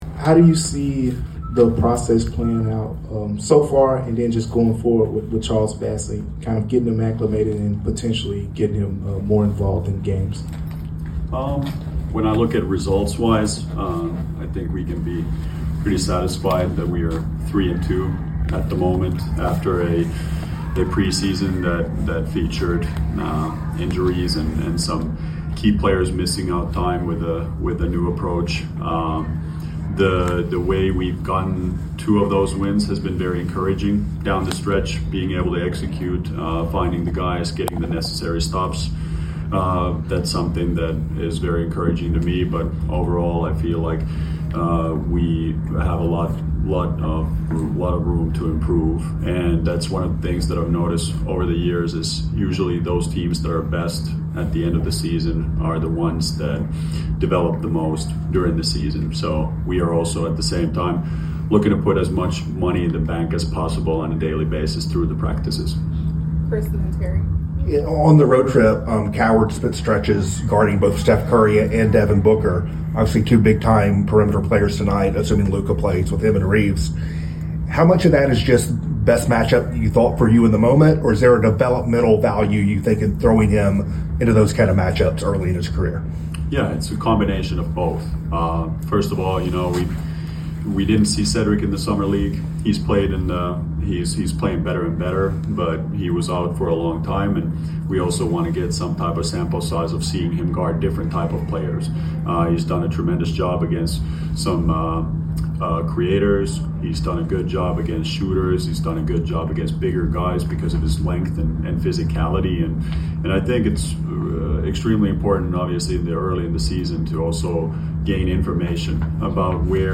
Memphis Grizzlies Coach Tuomas Iisalo Pregame Interview before taking on the Los Angeles Lakers at FedExForum.